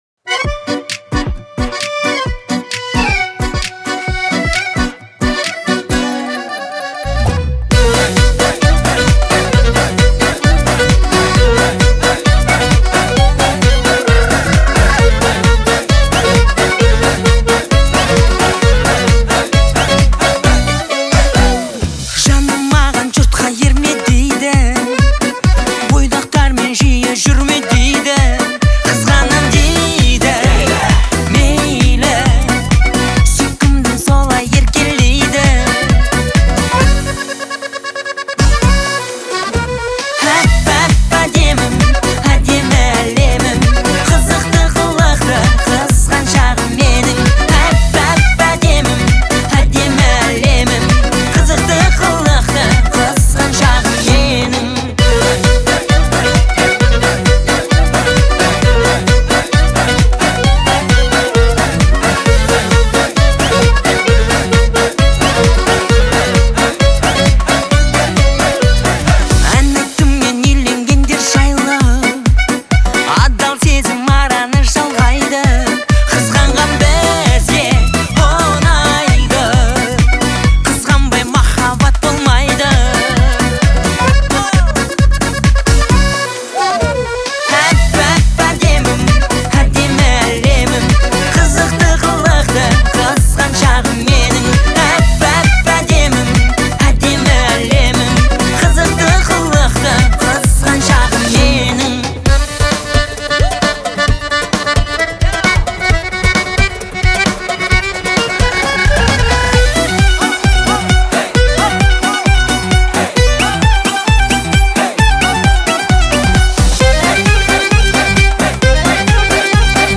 представляет собой трогательный пример казахской поп-музыки.